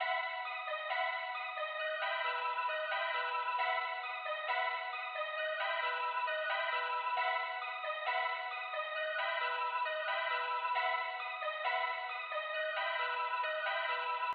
Krazy [134] Piano.wav